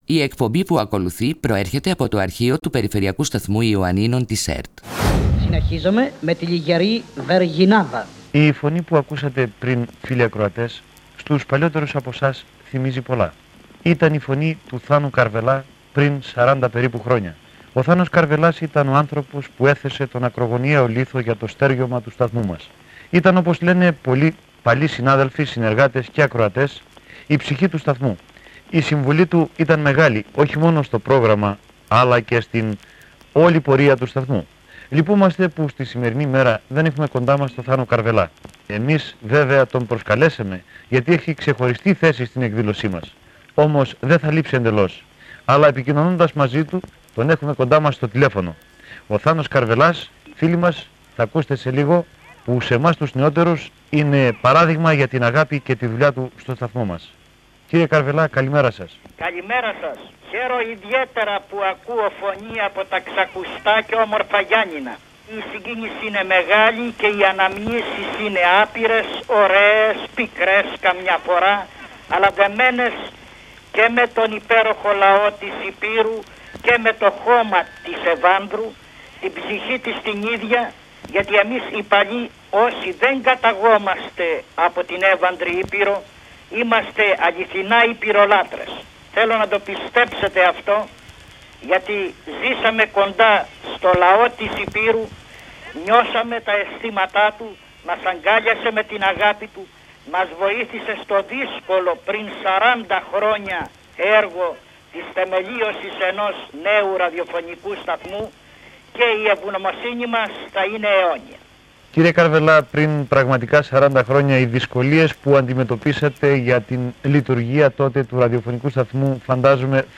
Ο Περιφερειακός Σταθμός Ιωαννίνων μοιράζεται on demand από το ραδιοφωνικό του αρχείο μία σπάνια συνέντευξη που παραχώρησε το 1988